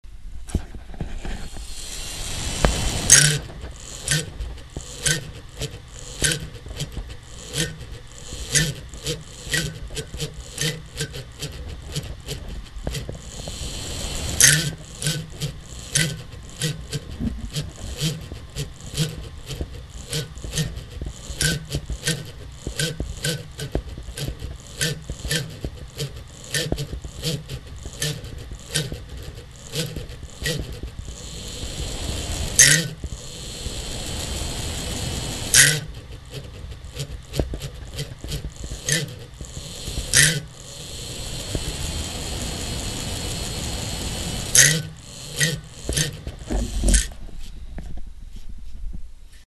9. A recording of the graphics card fan in my computer doing its motorcycle impression.
Posts: 1600   I don't know about deafening the user - but that sounds like a decidedly unhealthy fan.
fan.mp3